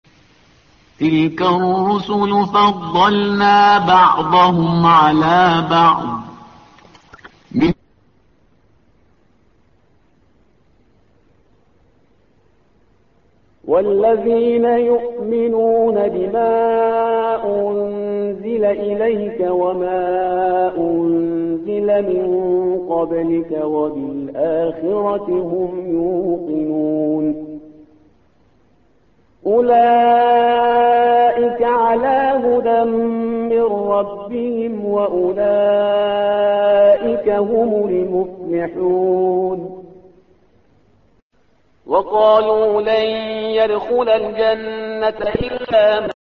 تحميل : الصفحة رقم 42 / القارئ شهريار برهيزكار / القرآن الكريم / موقع يا حسين